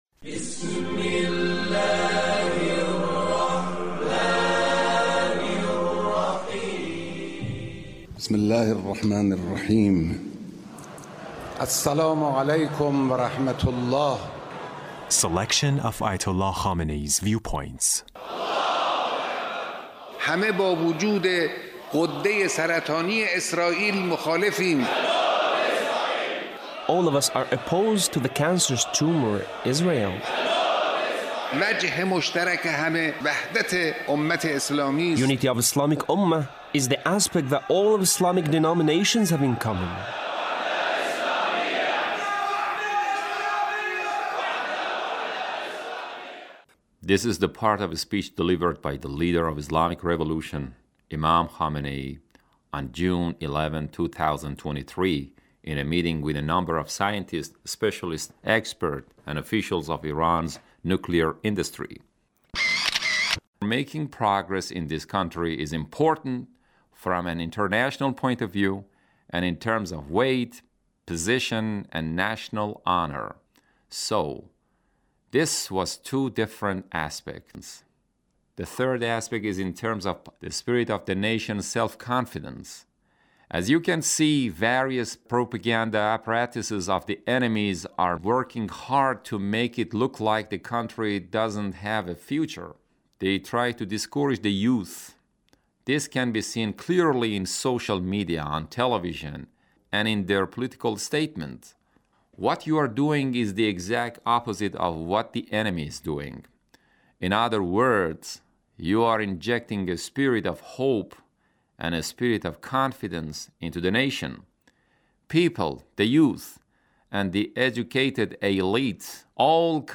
Leader's Speech about Nuclear Energy of Iran